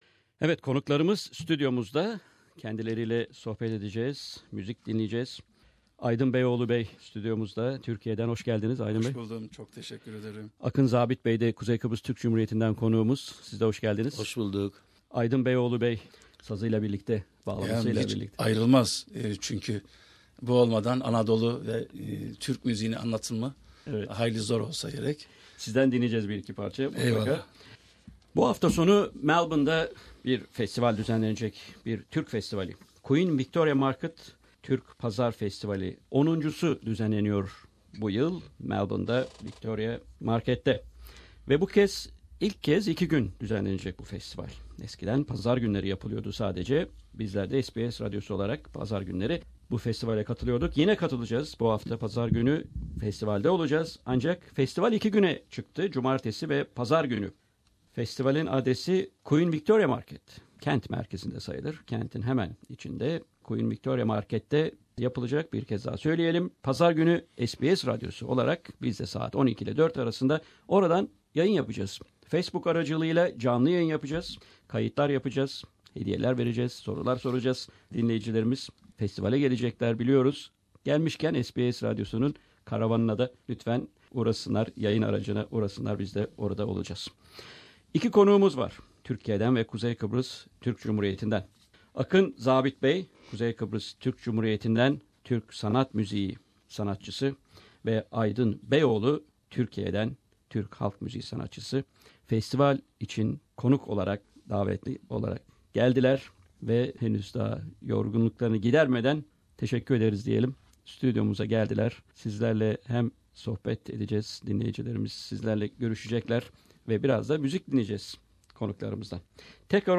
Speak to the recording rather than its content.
We had a live interview with the guests at the SBS studios.